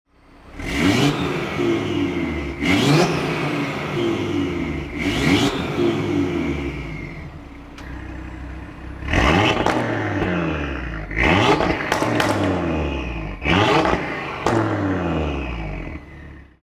Listen to the 5-Cylinder Fury!
• RS Sports Exhaust System with Black Oval Tips (£1,000)
• 2.5TFSI 5-Cylinder Engine: Aluminum Block Model
AJ18-PJU-Audi-RS3-8V-Ara-Blue-Revs.mp3